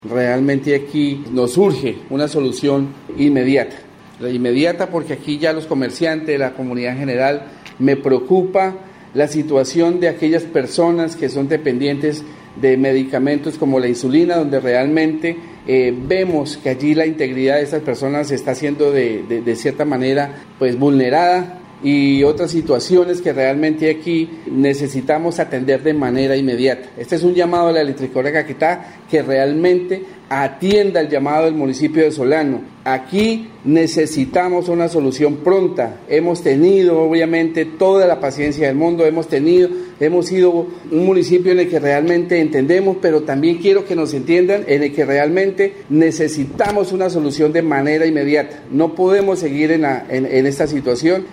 Por esa razón, el alcalde del municipio, Luis Hernando Gonzales Barreto, hizo un vehemente llamado a la Electrificadora del Caquetá, para que se logre una solución pronta a este inconveniente, y retorne la normalidad en la prestación del servicio.
ALCALDE_LUIS_GONZALES_BARRETO_ENERGIA_-_copia.mp3